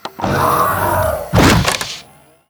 LiftDoorOpen.wav